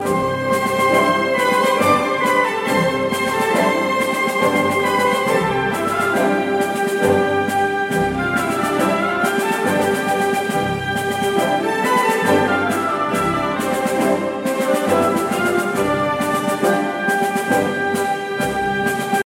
Categoria Classiche